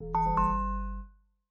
steeltonguedrum_eg.ogg